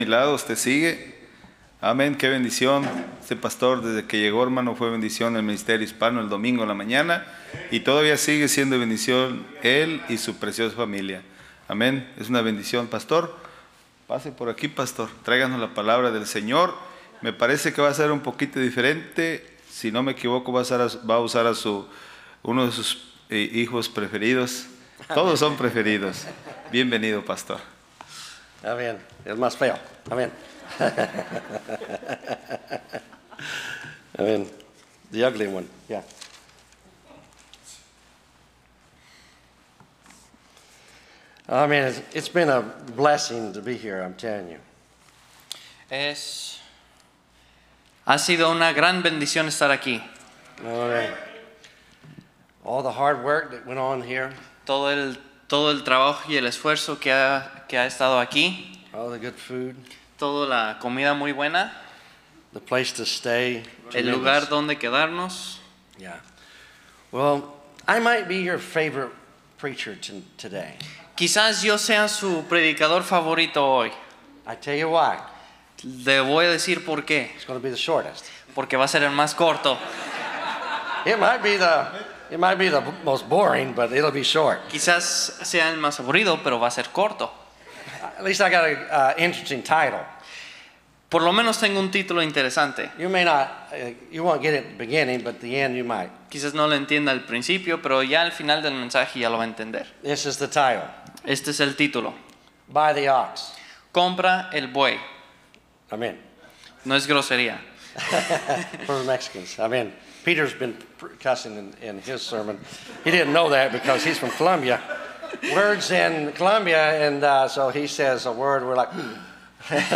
Series: Spanish Conference 2025
Preacher